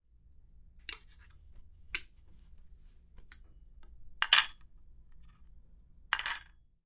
Dishes
描述：Dishes being moved around in a sink. Water pouring out of a cup. Simulation of someone doing dishes.
标签： kitchen pan dish clean silverware bang water knife clang spoon metal pot bowl cup dishes eating clank plate fork
声道立体声